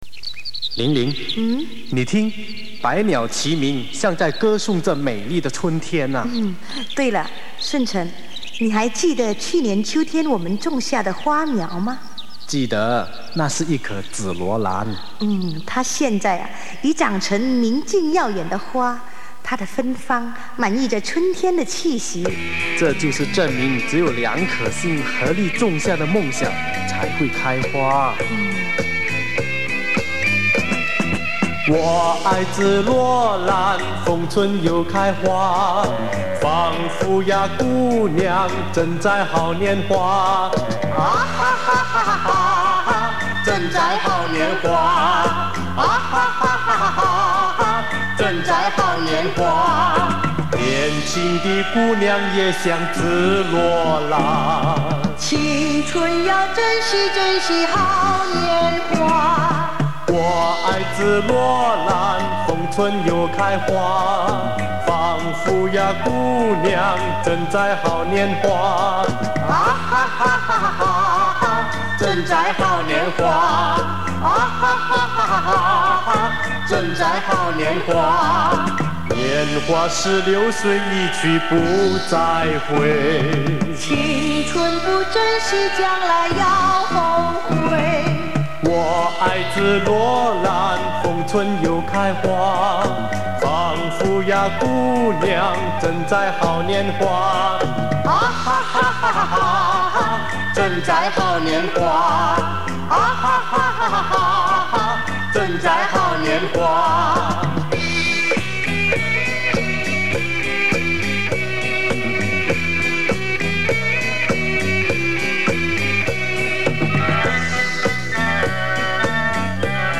联贯歌曲